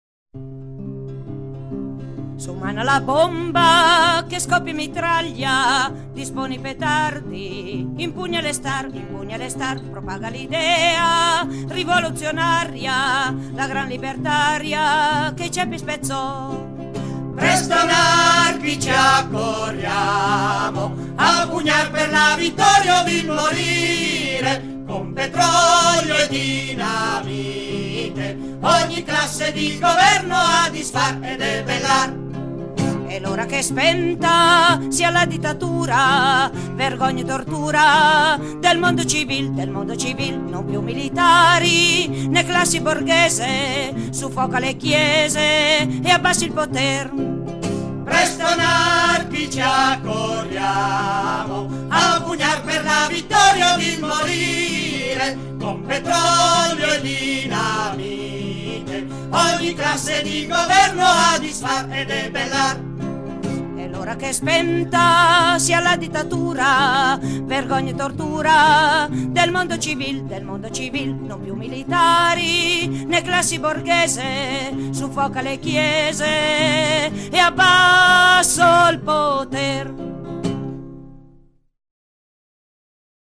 canzone anarchica